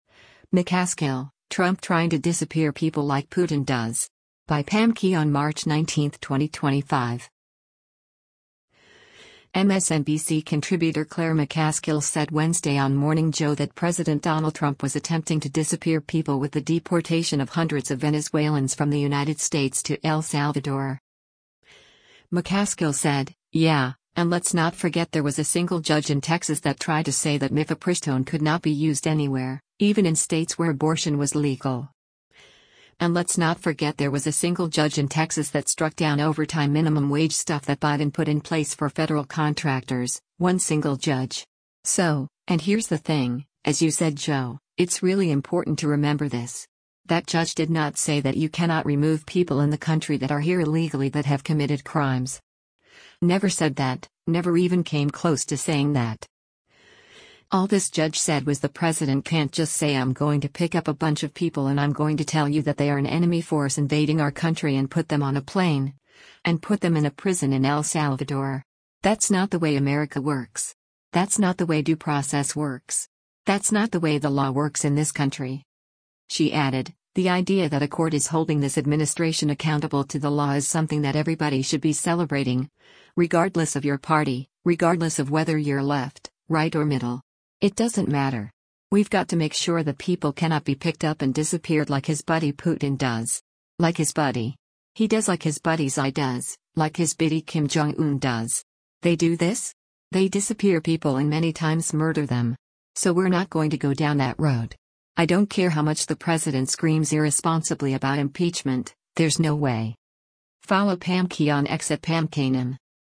MSNBC contributor Claire McCaskill said Wednesday on “Morning Joe” that President Donald Trump was attempting to “disappear people” with the deportation of hundreds of Venezuelans from the United States to El Salvador.